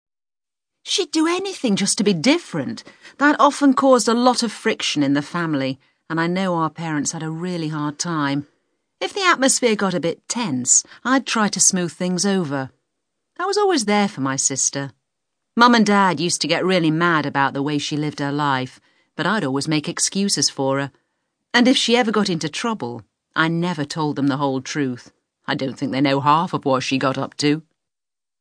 You will hear five people talking about their sisters.
SPEAKER 2,